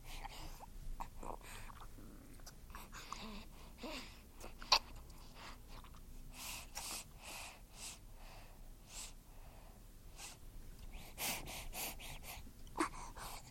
Звук малыша, сосущего материнское молоко